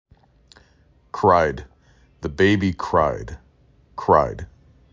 kr I d
Local Voices
North Carolina